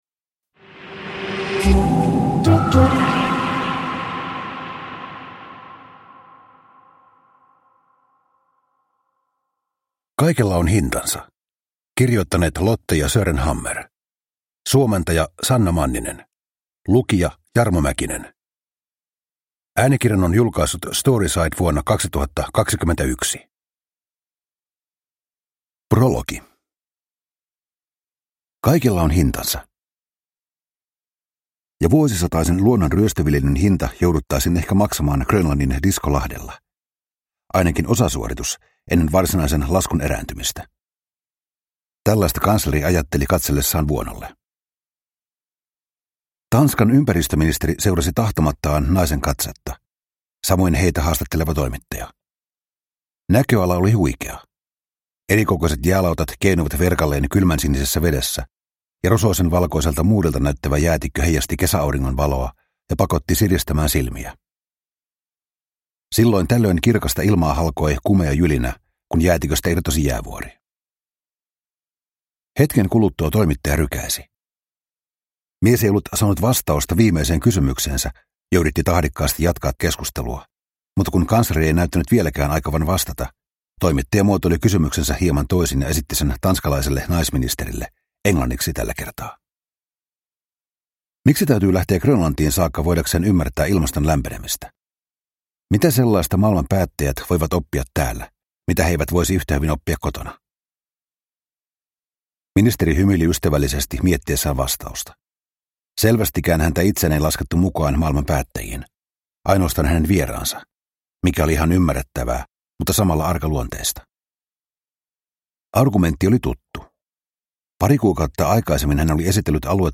Kaikella on hintansa – Ljudbok – Laddas ner